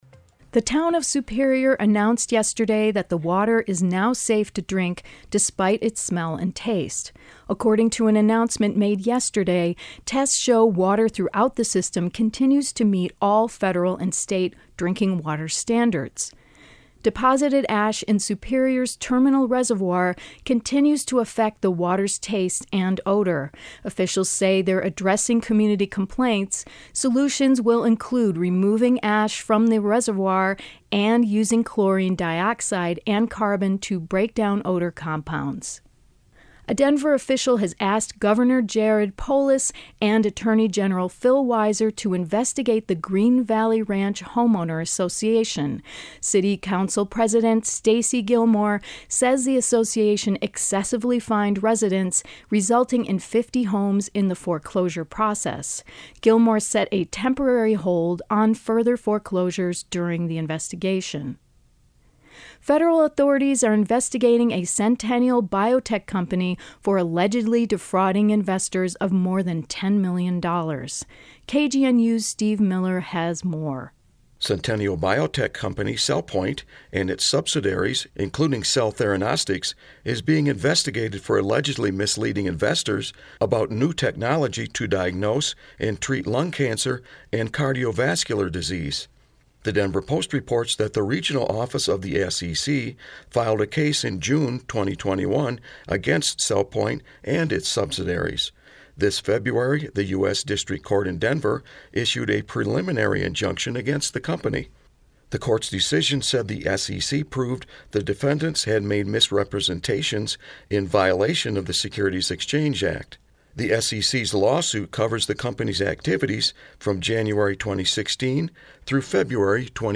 Headlines — March 18, 2022